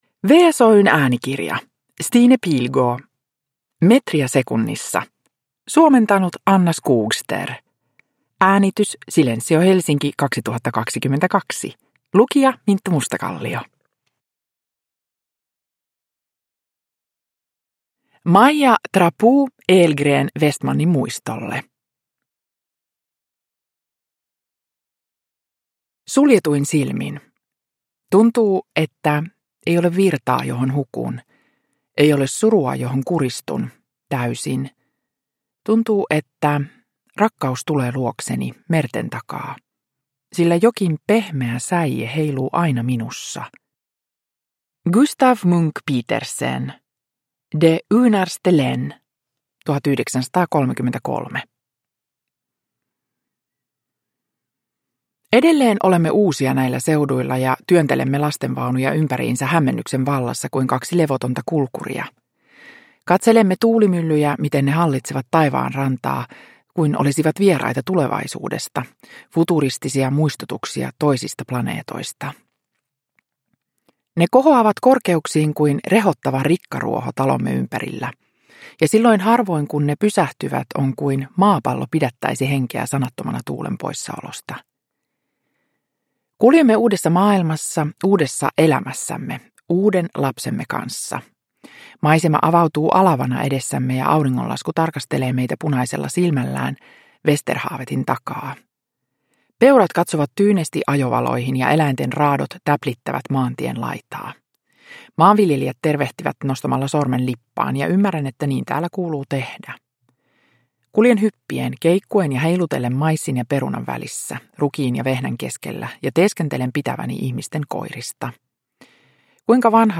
Metriä sekunnissa – Ljudbok – Laddas ner
Uppläsare: Minttu Mustakallio